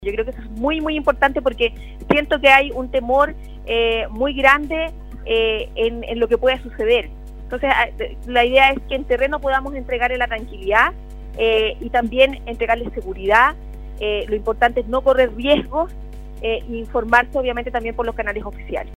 La tarde de este martes, el alcalde de la comuna de Tierra Amarilla, Mario Morales, sostuvo un contacto telefónico con Nostálgica donde informó que se encontraban en terreno  con la gobernadora de Copiapó, Paulina Bassaure y la seremi de Bienes Nacionales Carla Guaita, evaluando la situación de la comuna luego de las precipitaciones que se han presentado en la región de Atacama.